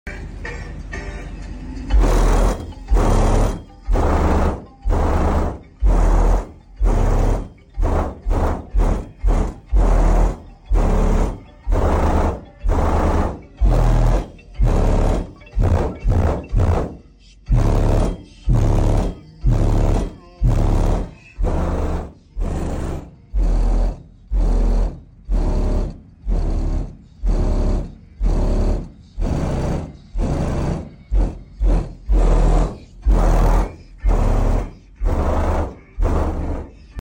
Windshield is flexing like crazy